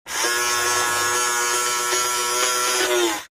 fo_toy_motor_05_hpx
Small toy motor spins at variable speeds. Motor, Toy Buzz, Motor